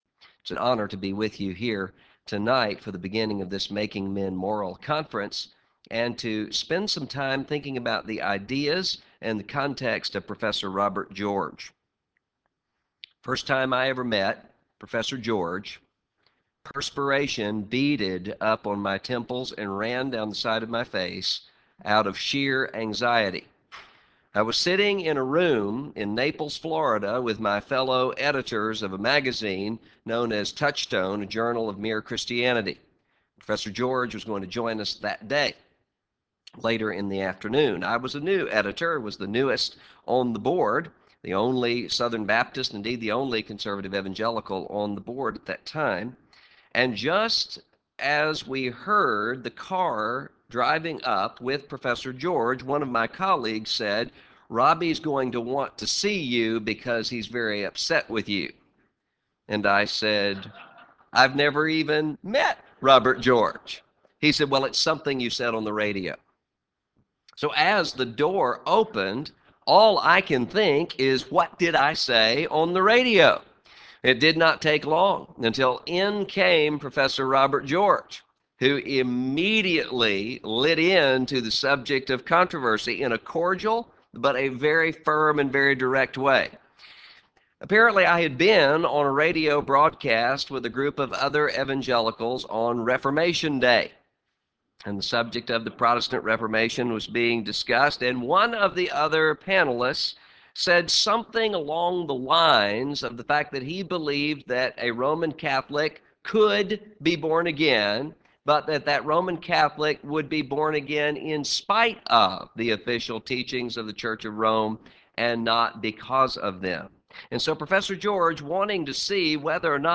Prof. of Christian Theology, The Southern Baptist Theological Seminary Address: Wed. Evening Address - Russell Moore Recording Date: Feb 25, 2009, 7:30 p.m. Length: 57:14 Format(s): WindowsMedia Audio ; RealAudio ; MP3 ;